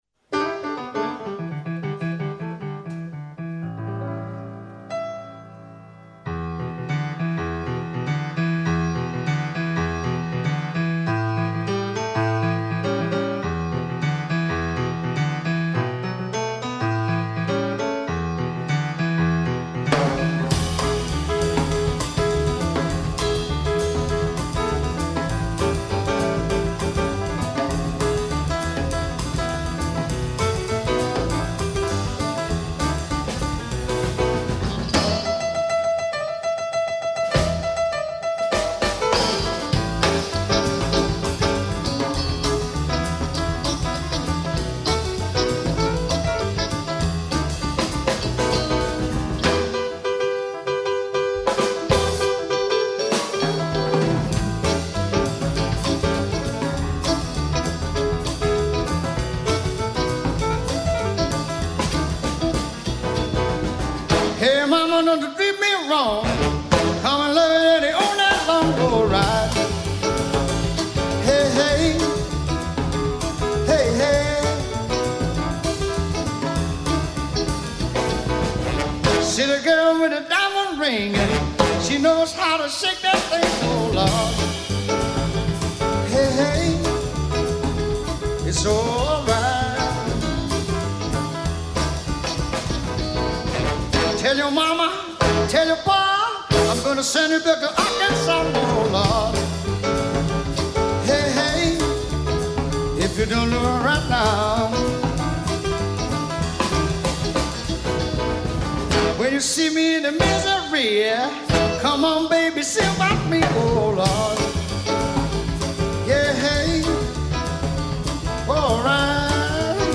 en concert
choeurs, chant
saxophone ténor
guitare
contrebasse
batterie